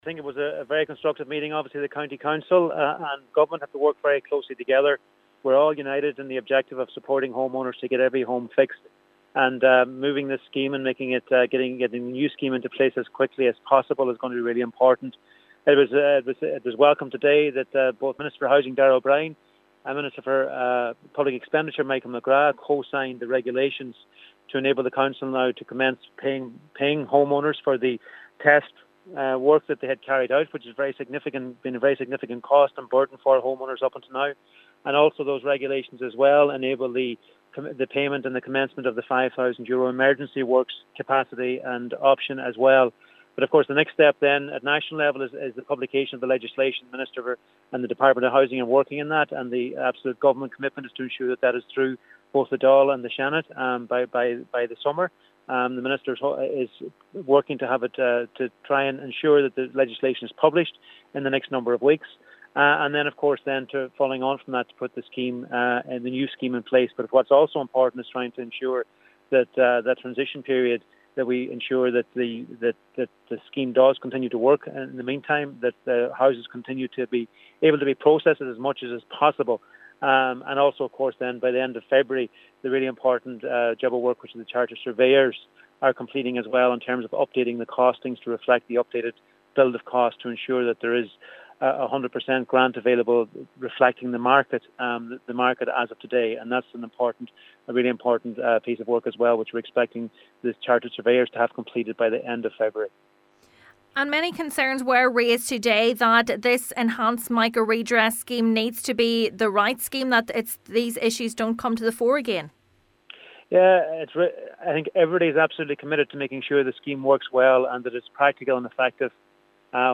He was present at a special Mica meeting of Donegal County Council today.
Minister McConalogue says Housing Minister Darragh O’Brien has committed to ensuring legislation for the enhanced Mica Redress Scheme is passed through the Dail by summer: